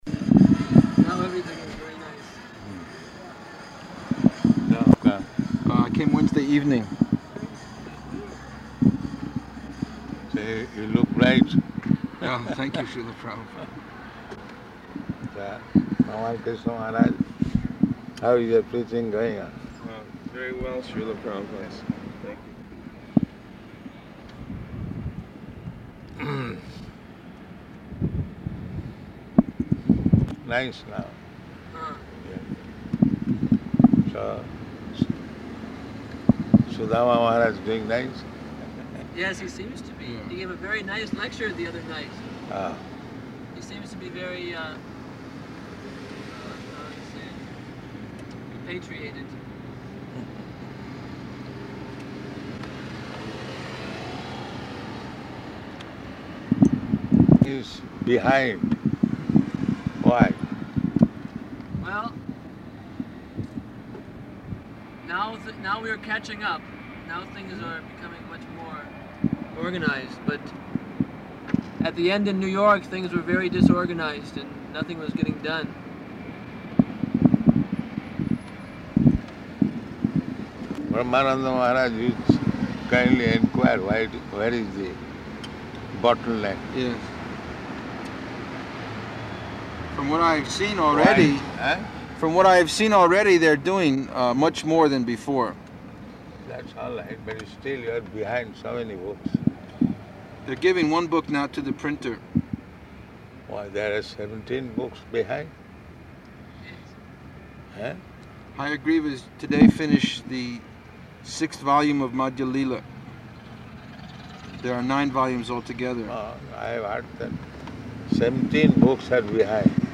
Arrival Conversation in Car & Room
-- Type: Lectures and Addresses Dated: June 20th 1975 Location: Los Angeles Audio file